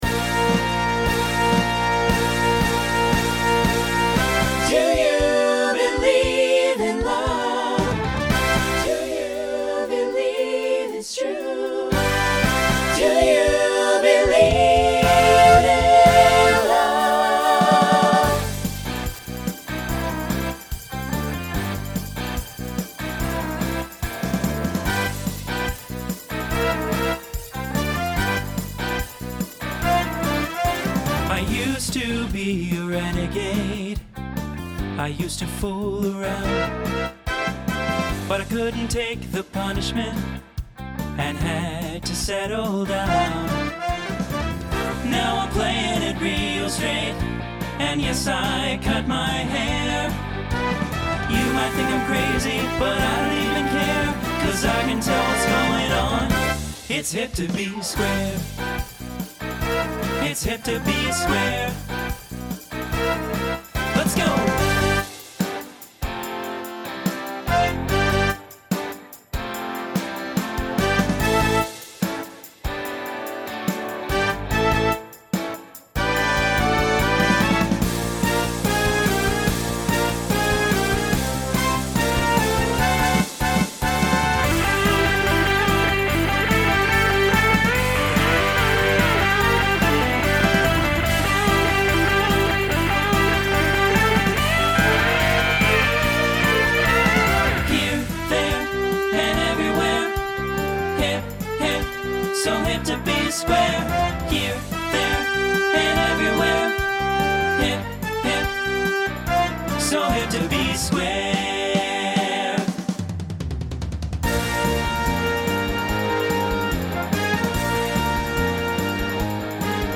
Rock
Voicing Mixed